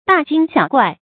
注音：ㄉㄚˋ ㄐㄧㄥ ㄒㄧㄠˇ ㄍㄨㄞˋ
讀音讀法：